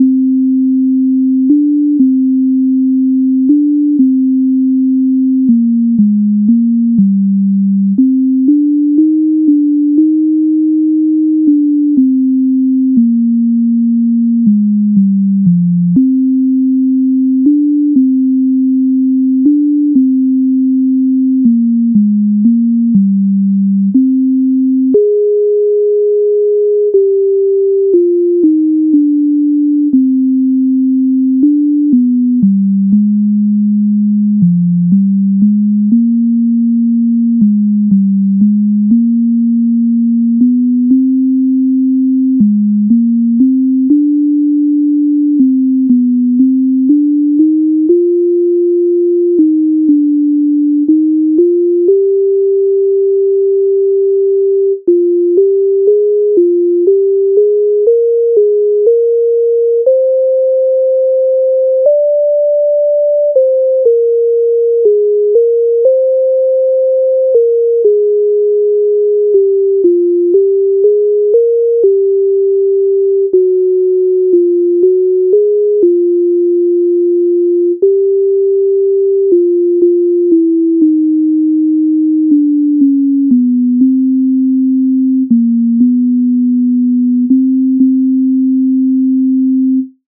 MIDI файл завантажено в тональності c-moll